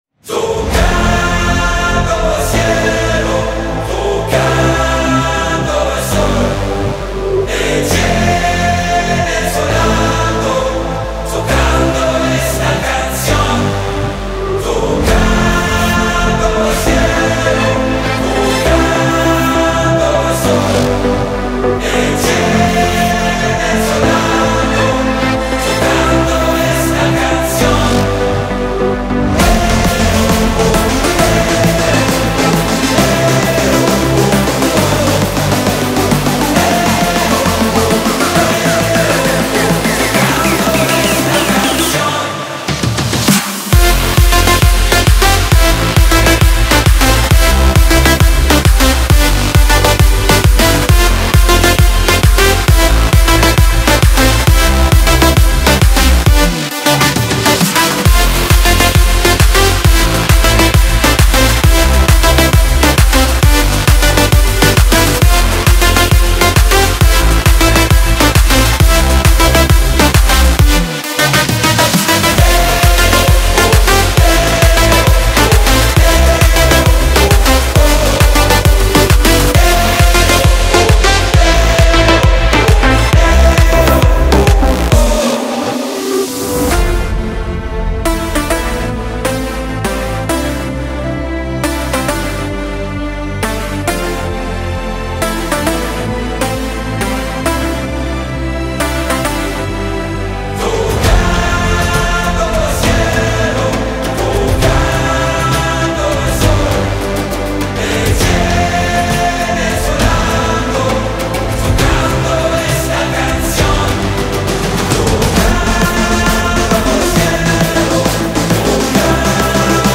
Клубная